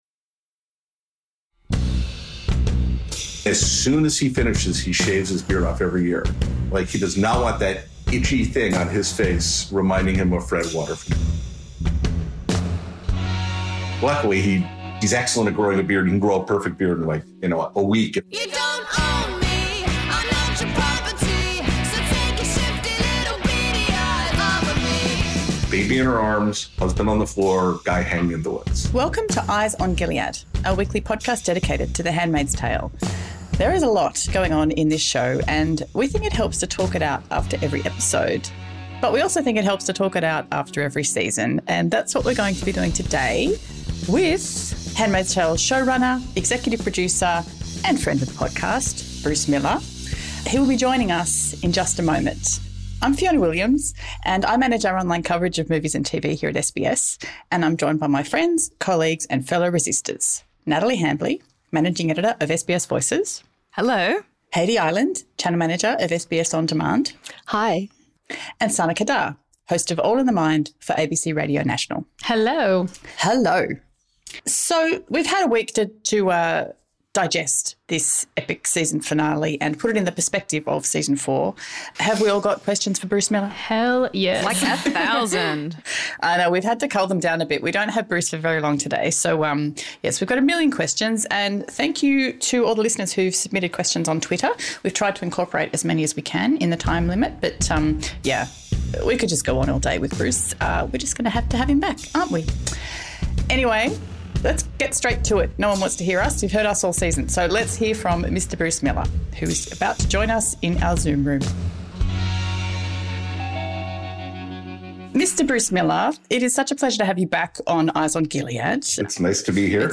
Bonus episode: Bruce Miller interview / Season 4 recap
With the epic finale of 'The Handmaids Tale' season 4 fresh in our minds, series creator/showrunner and friend of the podcast, Bruce Miller, jumps into our Zoom room to reflect back on the key storylines this season, and to answer our many, many questions (and yours, too!). This episode is a must for all Handmaid's Tale fans, and it also includes scoops about the character arcs and story threads that had to be sidelined due to COVID.